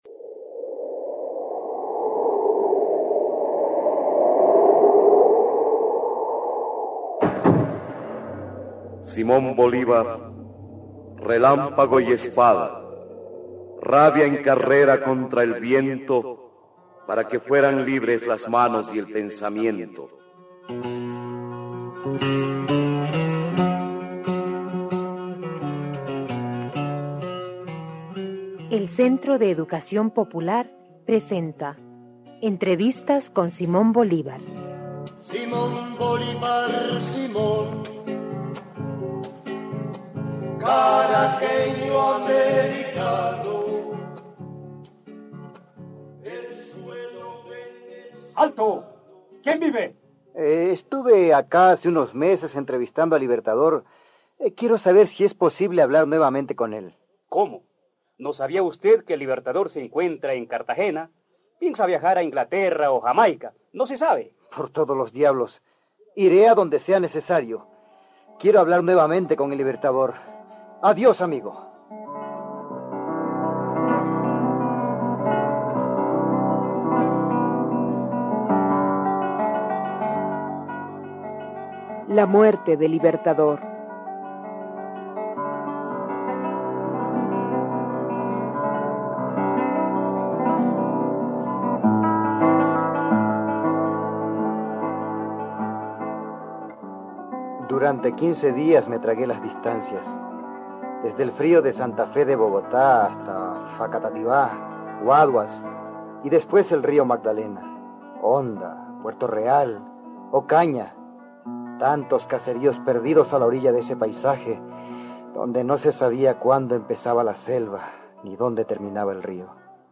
RADIOTEATRO: Entrevistas con Simón Bolívar (capítulo 8) – Central de Trabajadores y Trabajadoras de la Argentina